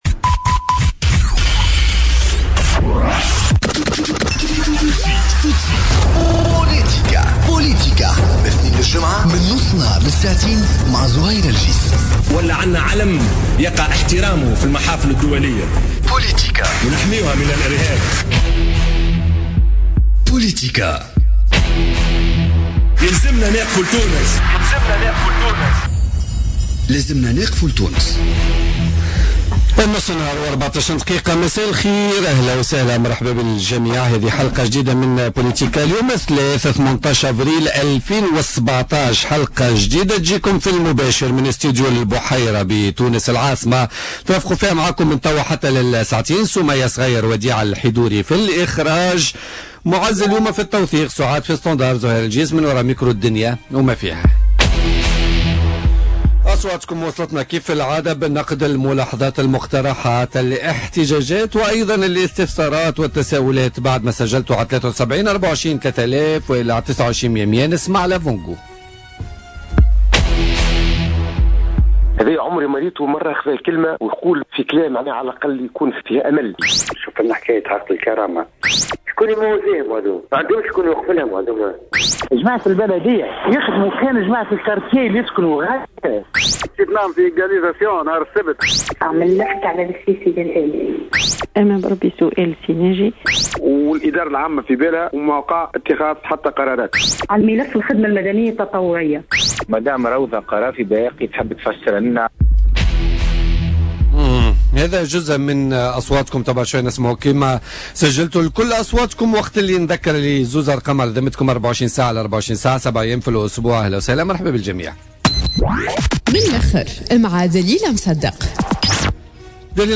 Samira Merai Friâa ministre de la Santé publique, invitée de politica